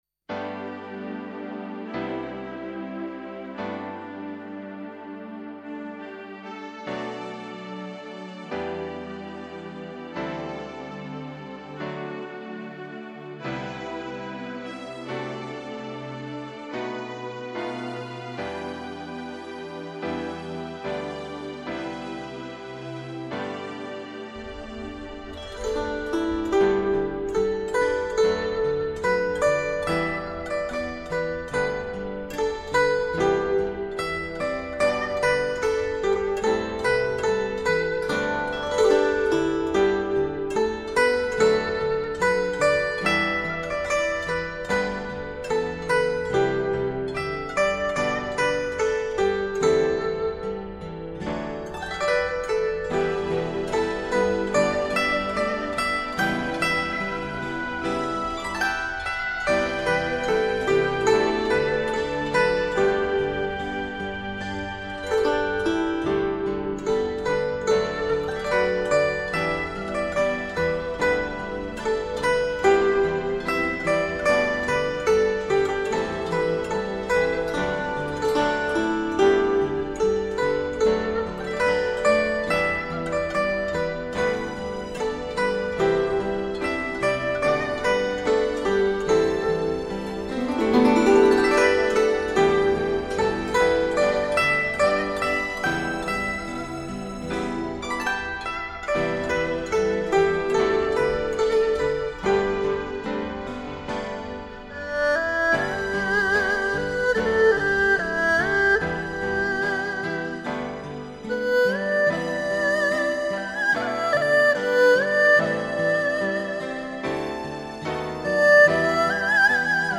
此系列编曲方面比较柔和一些，适合闲情时候欣赏的民乐器轻音乐。